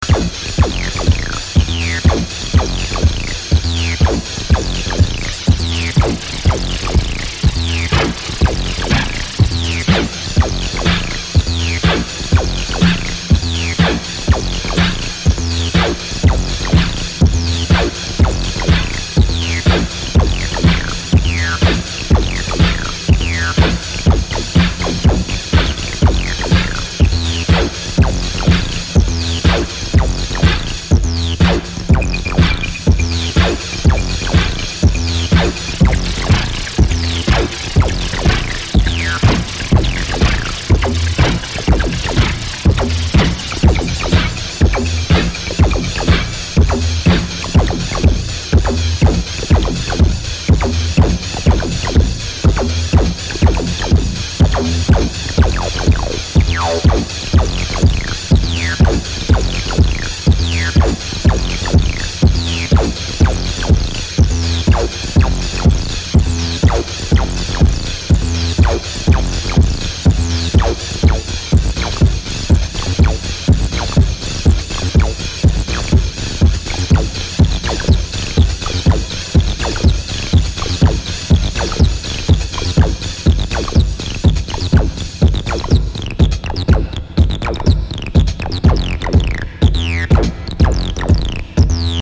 five acid fuelled productions
is a more skeletal affair
in full flight this is a seriously trippy track.